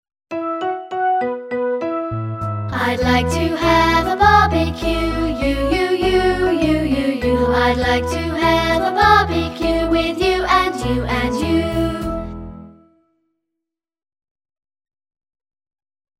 每个发音都配有一个用著名曲调填词的短歌和动作图示。